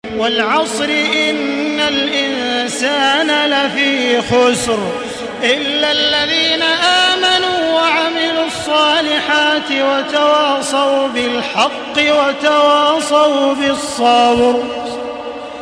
Surah Al-Asr MP3 in the Voice of Makkah Taraweeh 1435 in Hafs Narration
Murattal Hafs An Asim